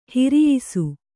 ♪ hiriyisu